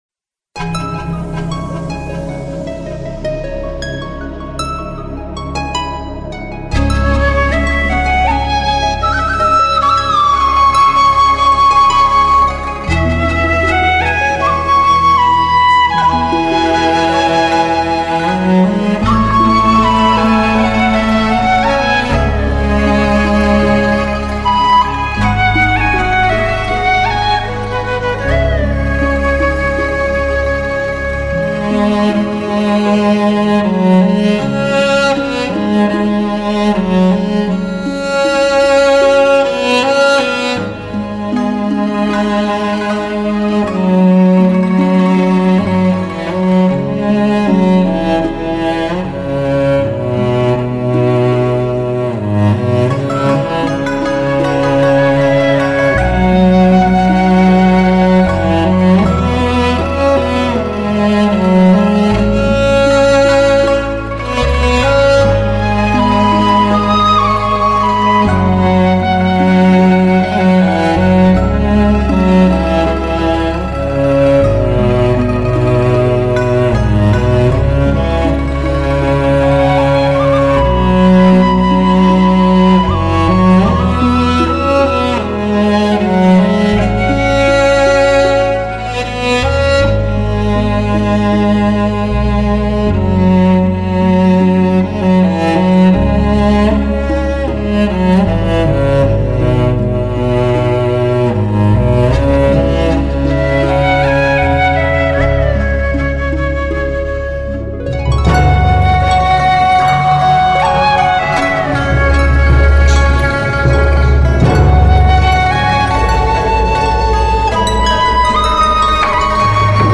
专辑格式：DTS-CD-5.1声道
顶级制作班底，“交响乐”般错落有致的编配，挑战传统国乐的听觉极限。
特别加强的低频力度，让民族乐器如同交响乐一样层次分明。
笛子、大提琴、琵琶